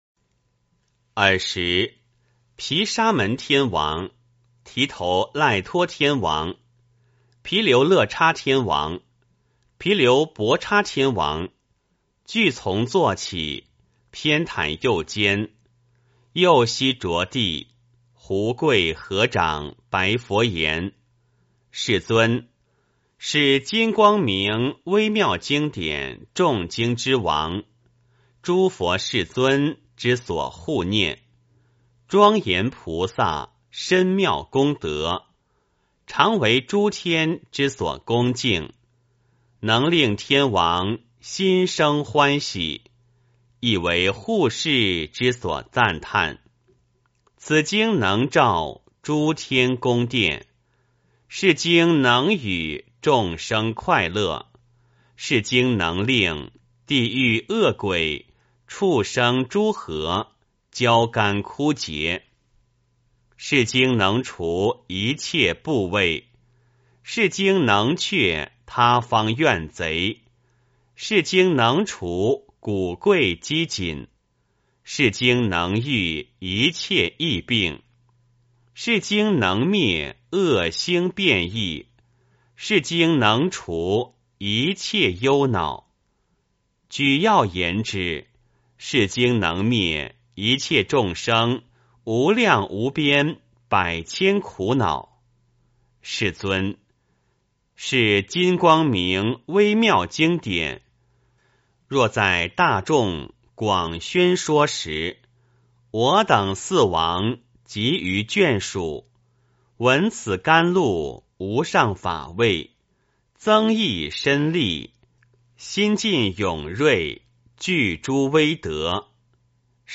金光明经06 - 诵经 - 云佛论坛